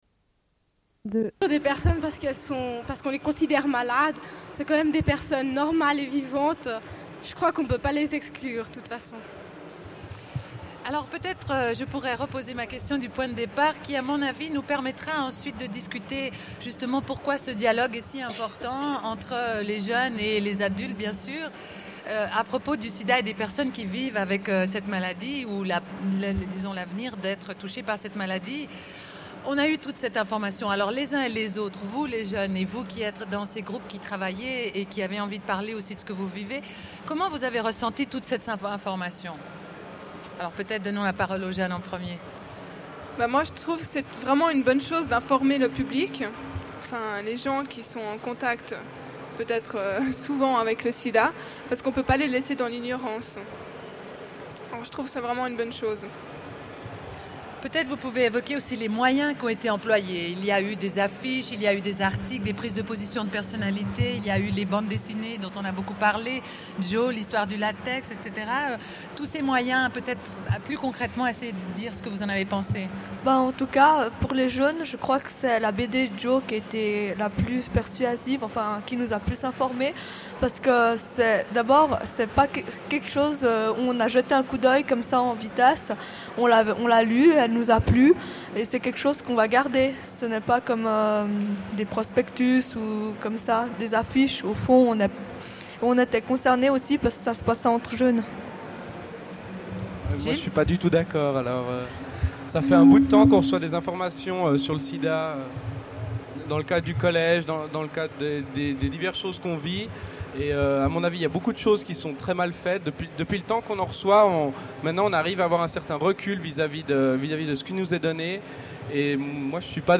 Une cassette audio, face A31:36